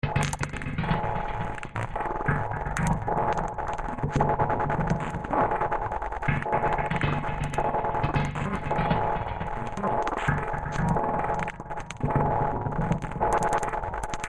Huh Eh Man
描述：A man is questioning something.
标签： man expression male silly dumb hae question men huh eh what
声道立体声